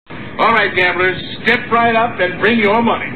Tags: seinfeld airhorn